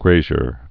(grāzhər)